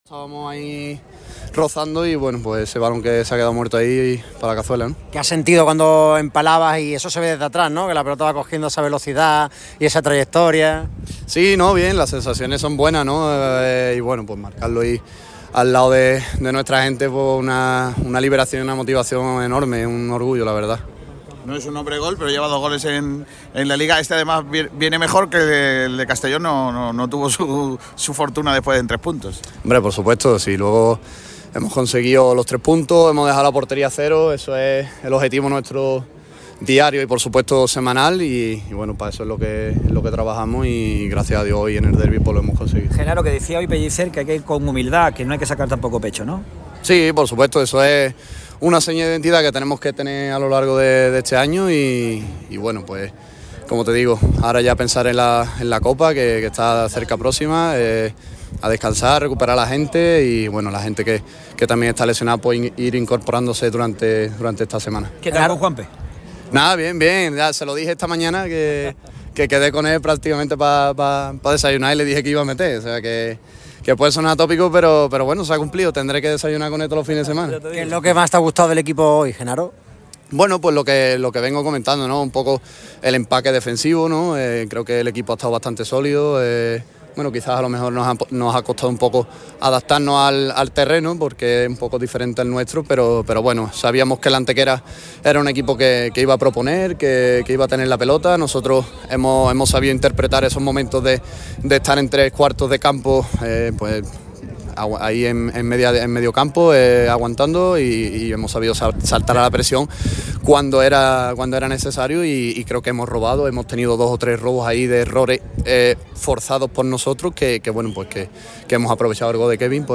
Al término del partido en El Maulí